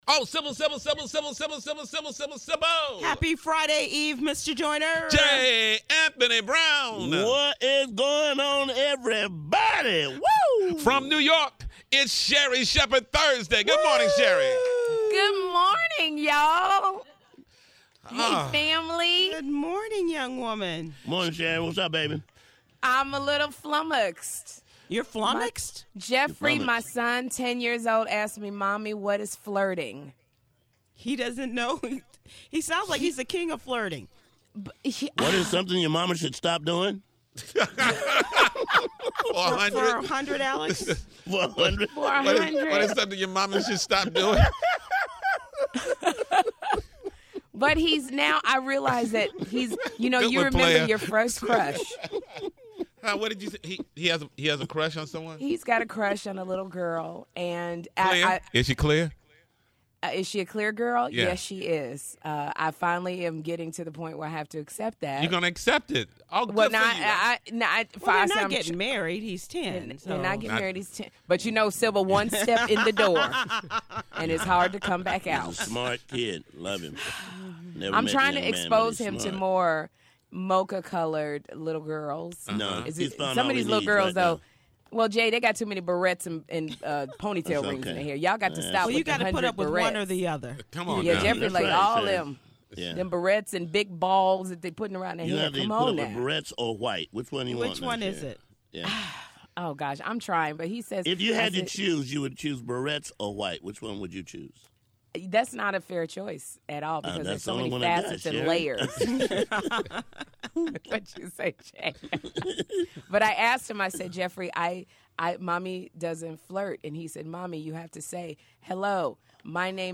5/28/15- It’s Sherri Shepherd Thursday and the TJMS crew are laughing it up on this Friday eve.